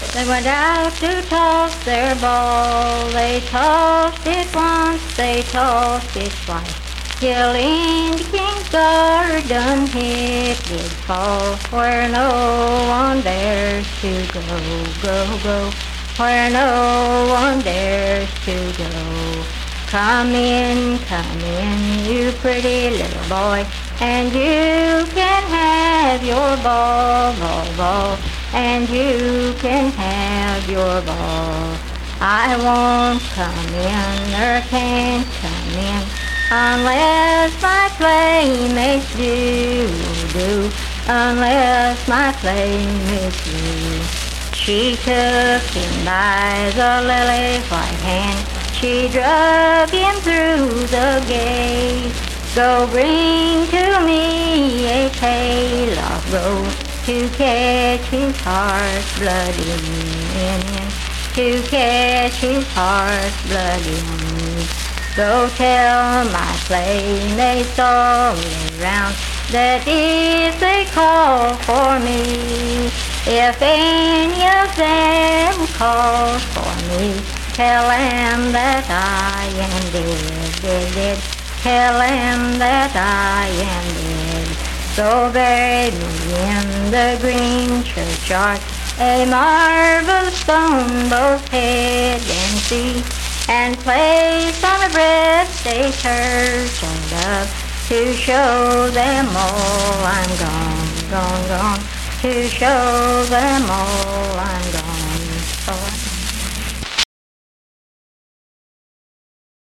Unaccompanied vocal music
Verse-refrain 2(5w/R).
Voice (sung)
Spencer (W. Va.), Roane County (W. Va.)